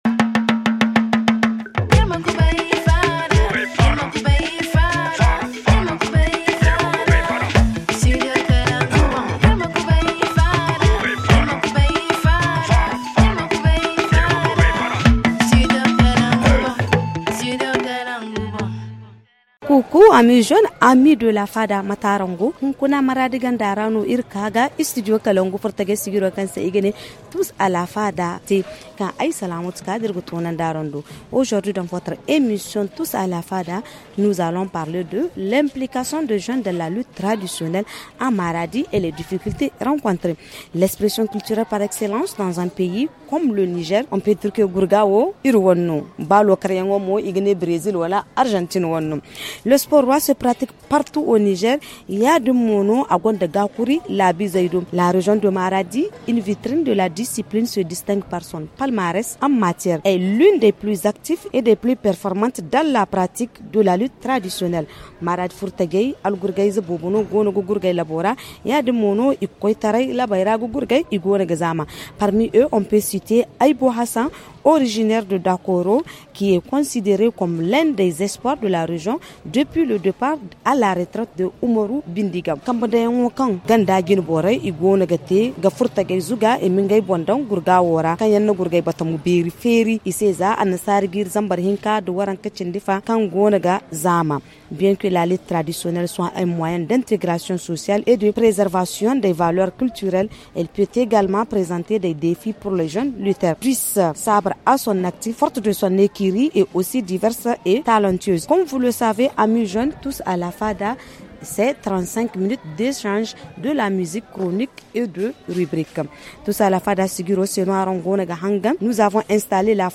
ZA-FR La Fada en franco-zarma Télécharger la Fada ici.